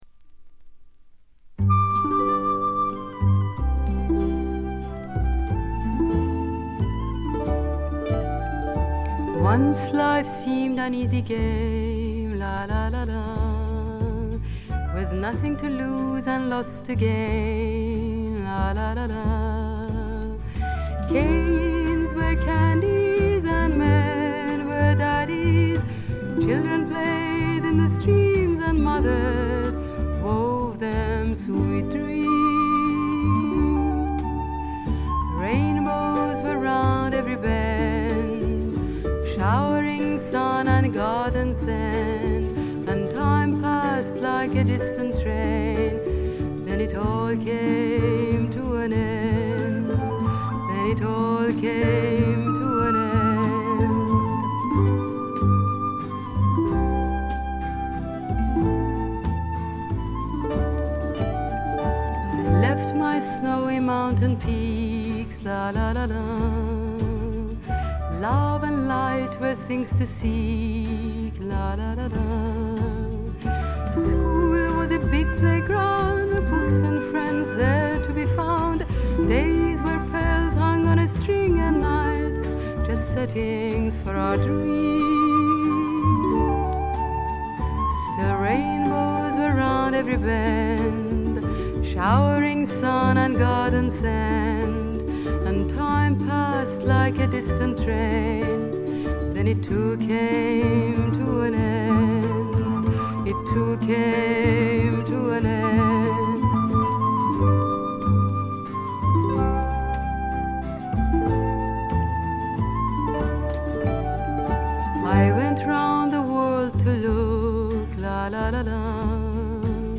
静音部でのバックグラウンドノイズ、軽微なチリプチ。
ともあれ、どんな曲をやってもぞくぞくするようなアルトヴォイスがすべてを持って行ってしまいますね。
試聴曲は現品からの取り込み音源です。